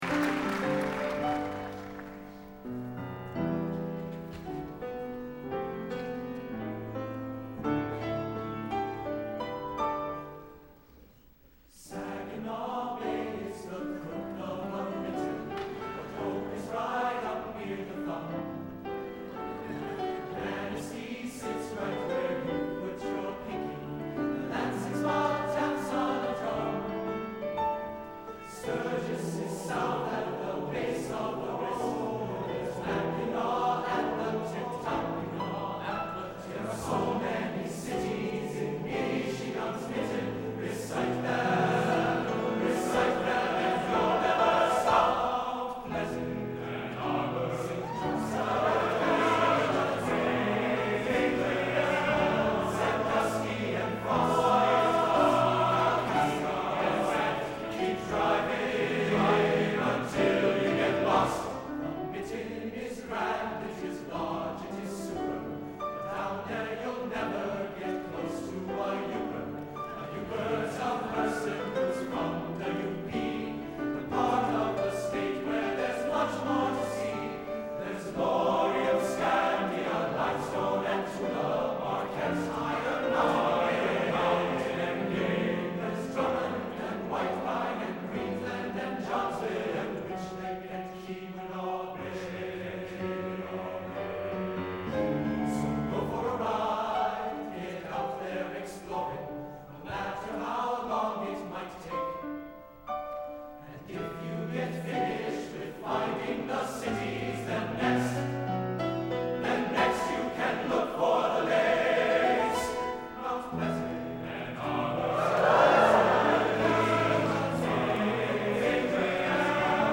TTBB, piano